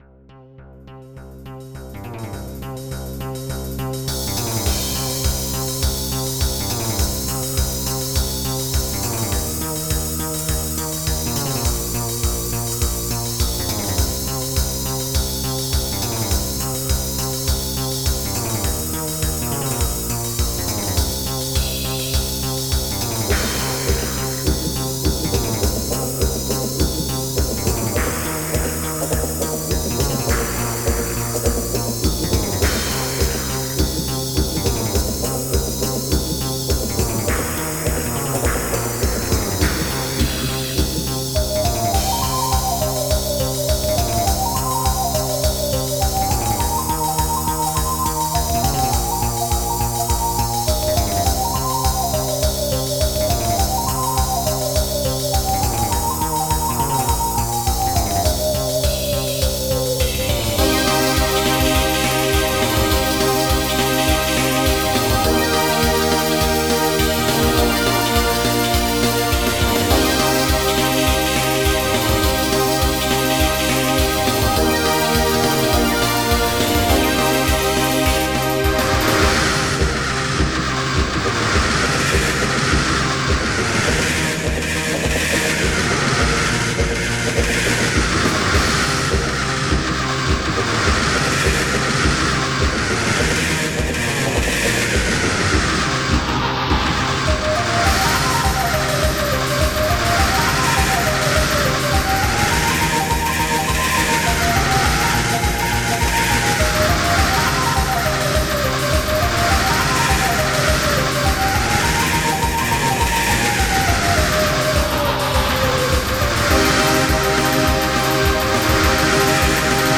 Egyptian theme cover